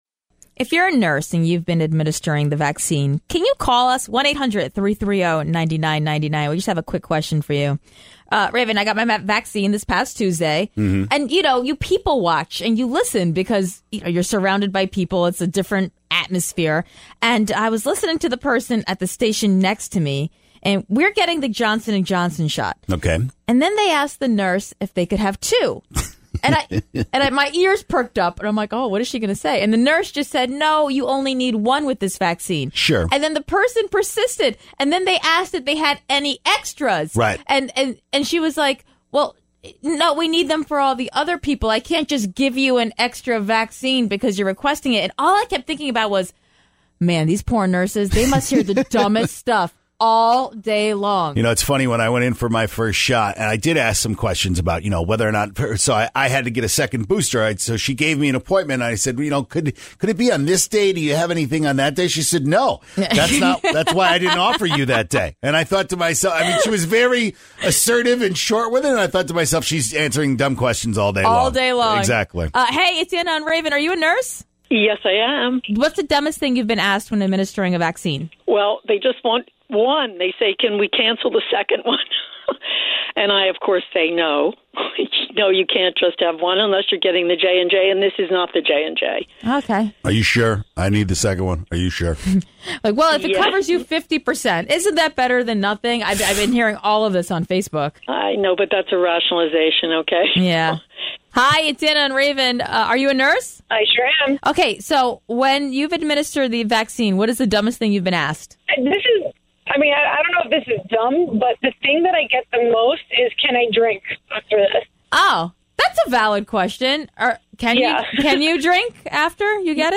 ask nurses to call in the dumbest questions they’ve heard about the vaccine!